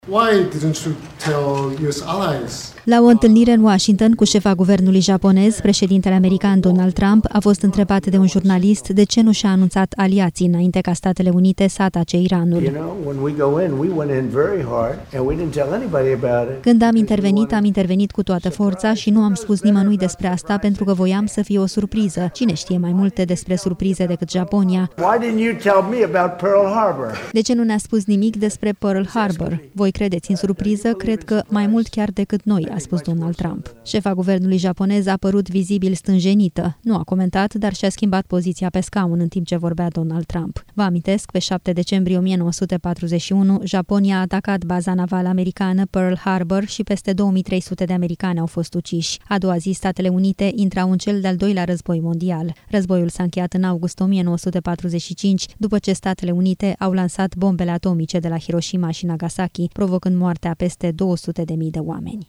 La o întâlnire în Washington cu șefa guvernului japonez, președintele american Donald Trump a fost întrebat de un jurnalist de ce nu și-a anunțat aliații înainte ca Statele Unite să atace Iranul: